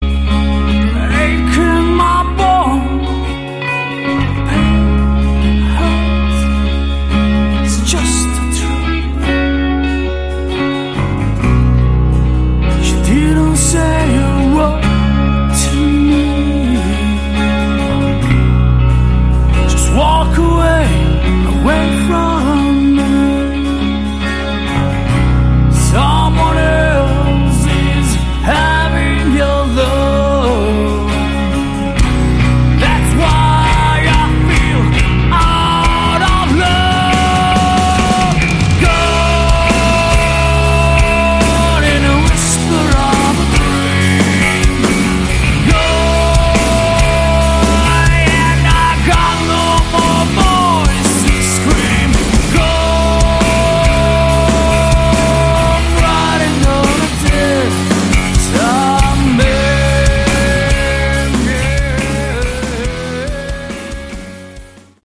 Category: Hard Rock
lead vocals
bass, backing vocals
guitar
drums